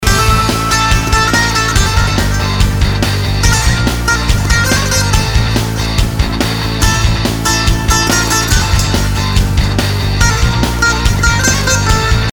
آهنگ موبایل احساسی_ورزشی با کلام(ریتم تند)